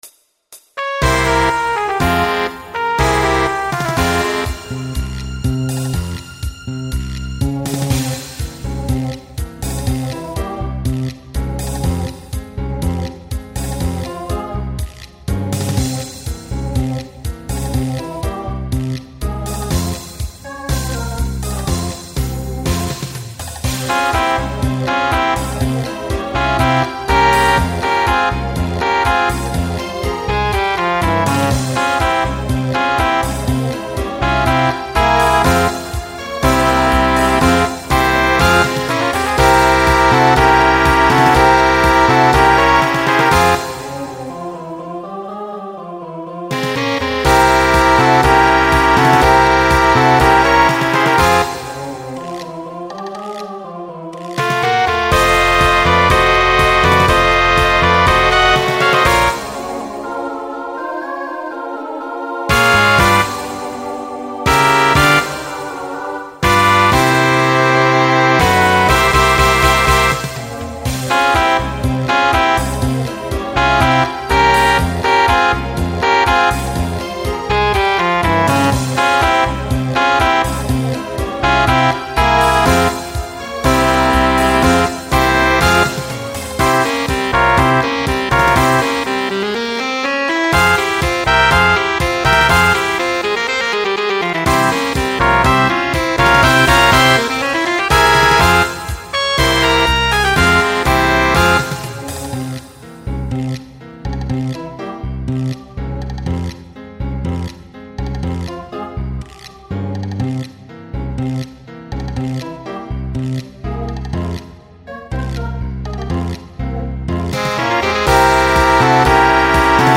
Voicing SATB Instrumental combo Genre Broadway/Film
Mid-tempo